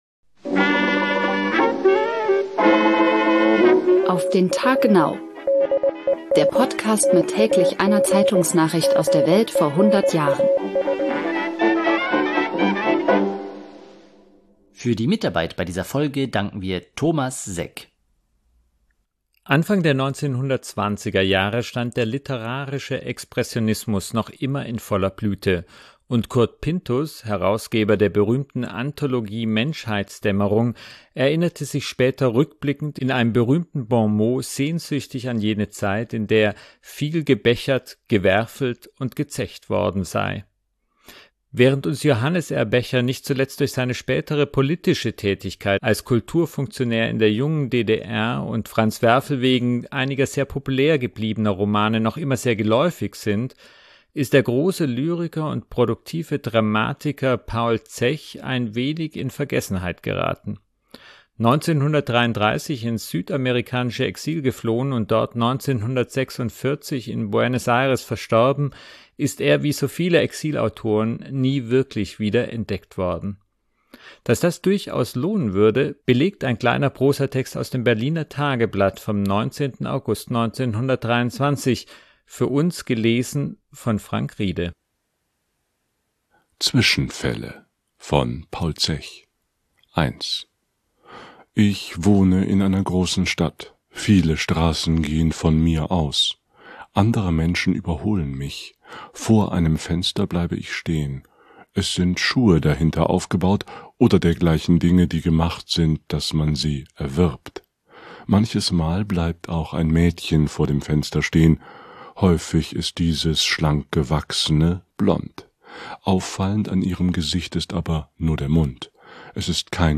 für uns gelesen